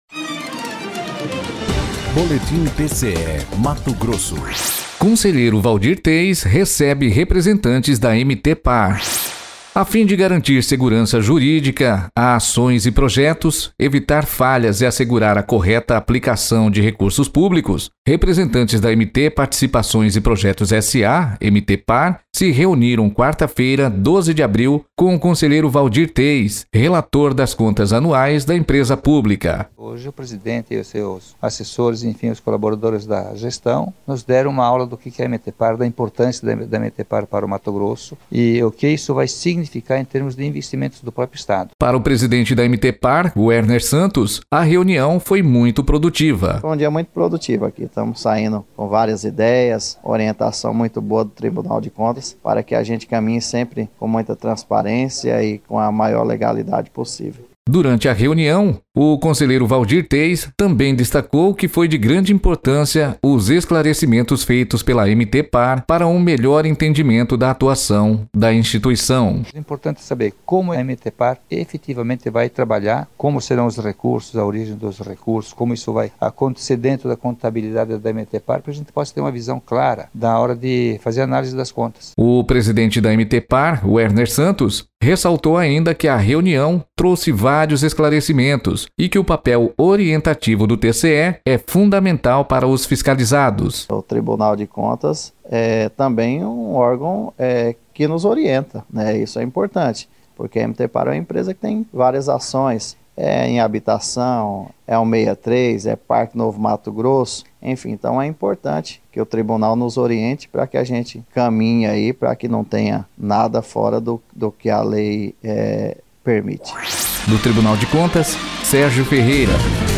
Sonora: Waldir Teis – conselheiro do TCE-MT